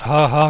haha.wav